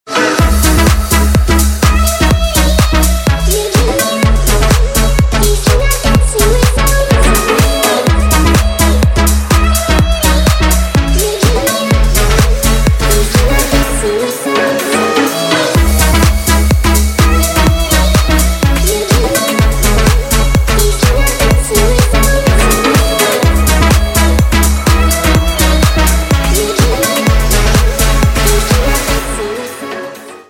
• Качество: 320, Stereo
dance
Electronic
future house
club
забавный голос